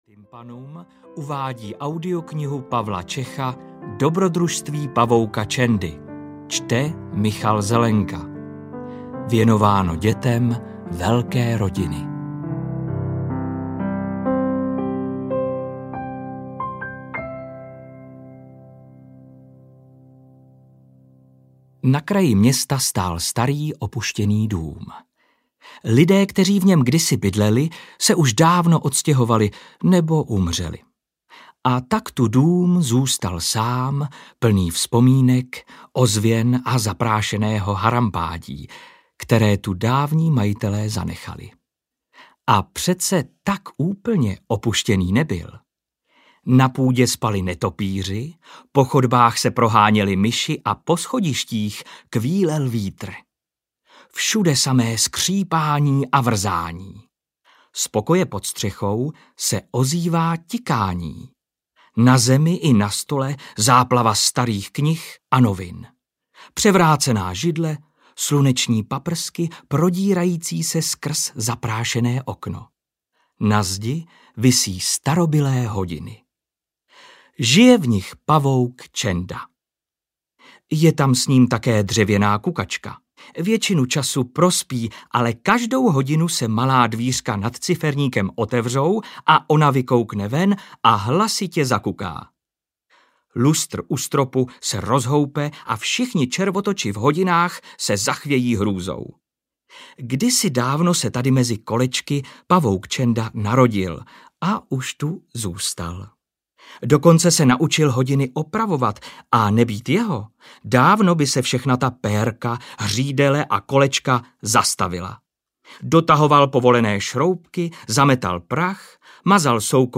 Dobrodružství pavouka Čendy audiokniha
Ukázka z knihy